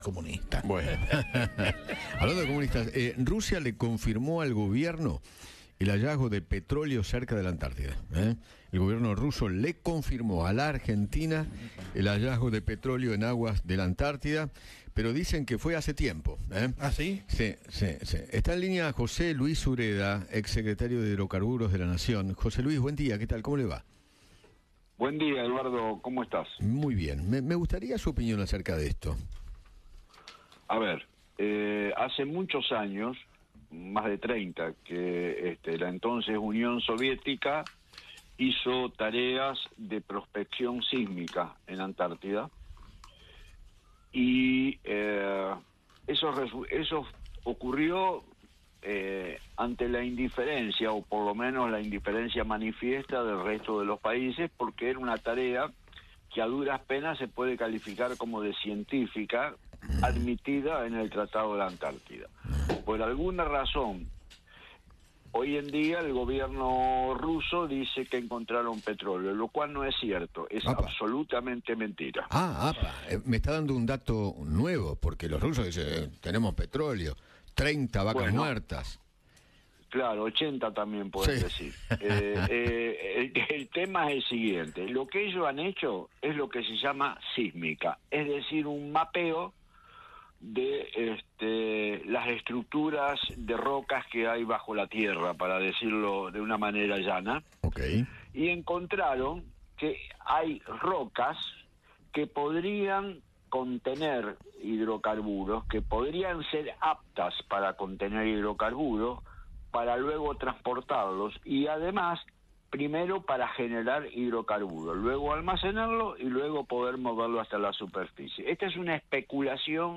José Luis Sureda, ex secretario de Hidrocarburos de Nación, conversó con Eduardo Feinmann sobre el hallazgo de petróleo que anunció Rusia cerca de la Antártida.